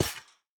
Monster Spawner step1 JE1 BE1.wav
Monster_Spawner_step1_JE1_BE1.wav